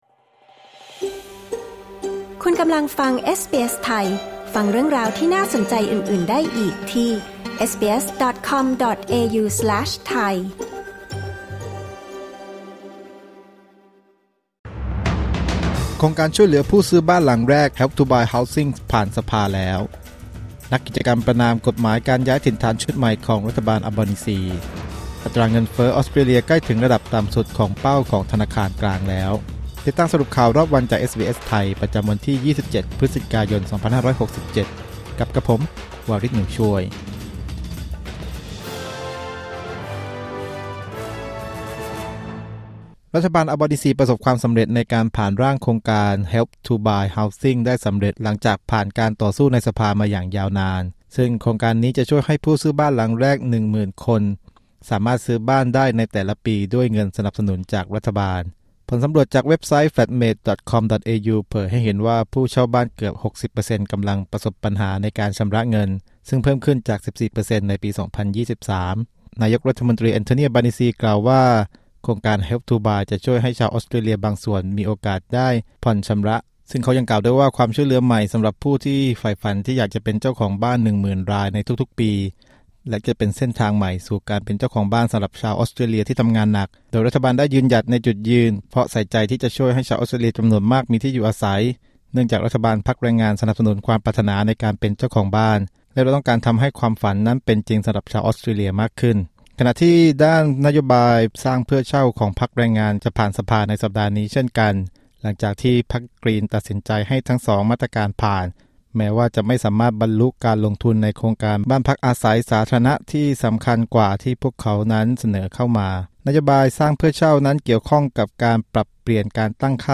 สรุปข่าวรอบวัน 27 พฤศจิกายน 2567